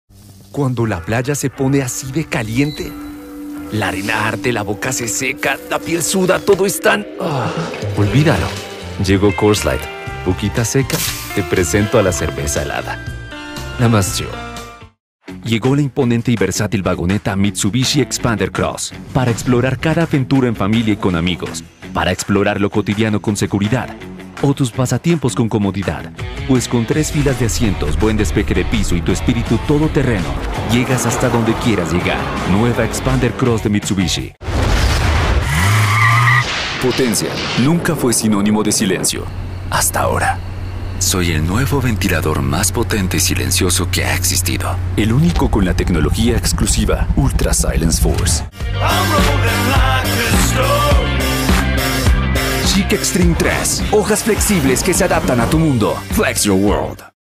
Spanish (Colombian)
Warm
Conversational
Friendly